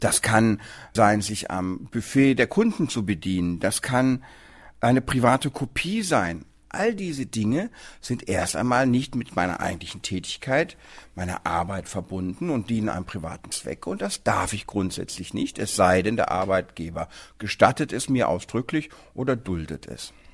O-Ton: Schon Bagatellfälle können zur Kündigung führen